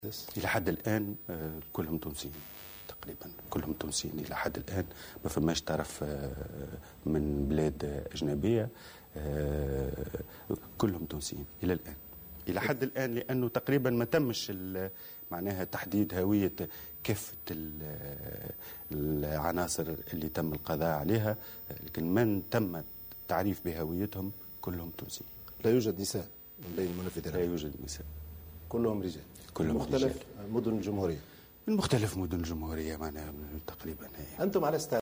أكد وزير الداخلية هادي مجدوب في مقابلة صحفية مع "فرانس 24" اليوم الثلاثاء، أنه لا يوجد نساء بين الارهابيين الذين تم القضاء عليهم او ايقافهم في عملية بنقردان.